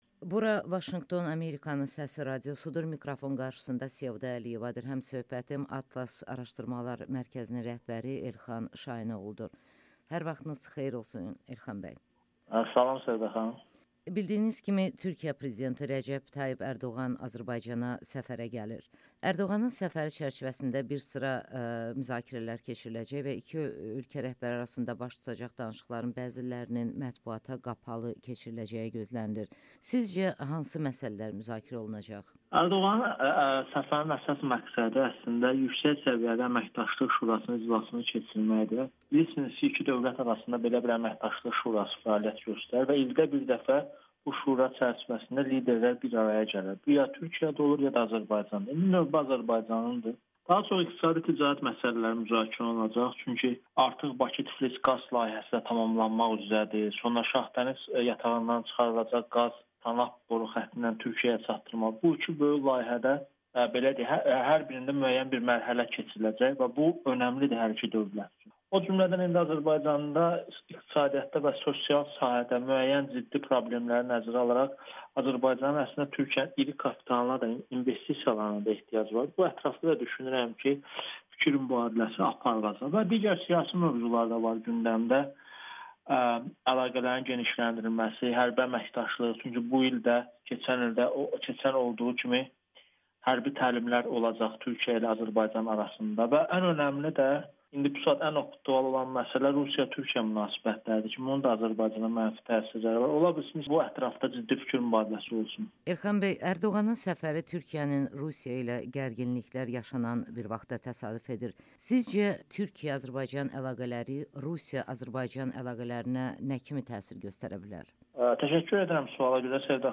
Rusiya-Türkiyə toqquşması Azərbaycanı seçimə məcbur edə bilər [Audio-Müsahibə]